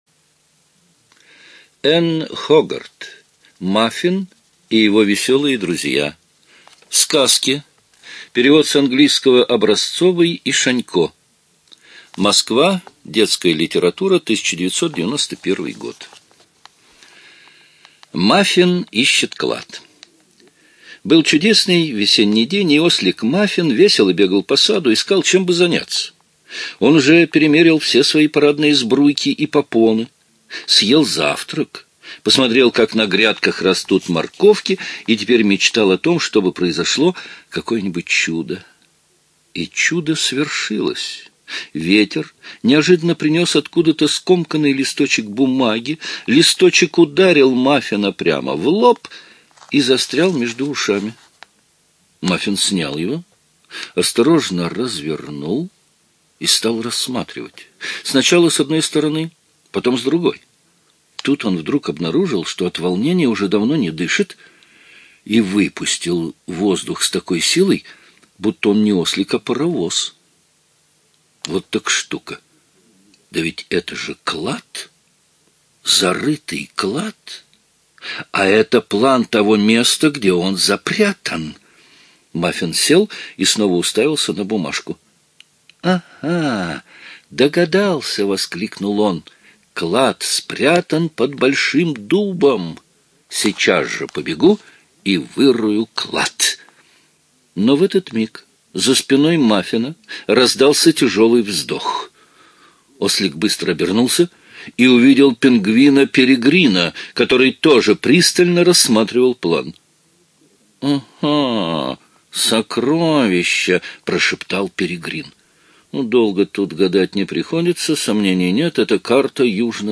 ЖанрДетская литература, Сказки
Студия звукозаписиЛогосвос